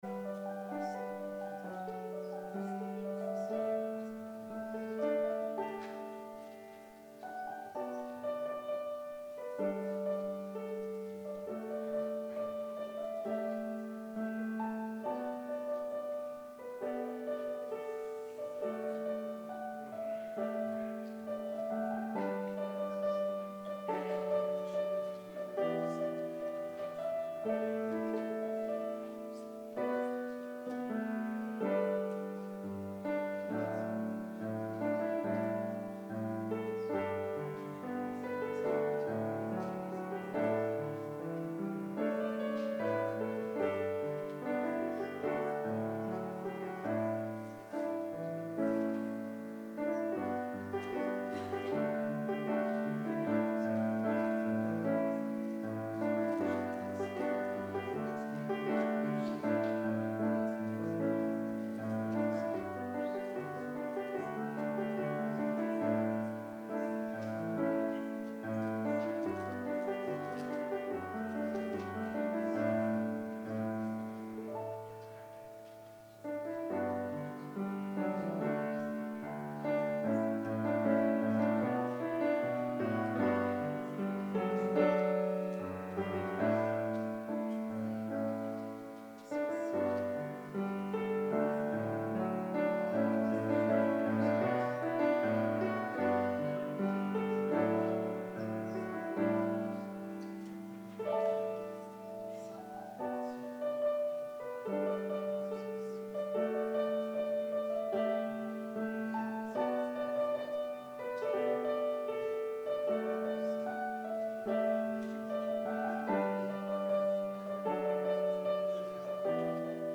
Thanksgiving Eve Service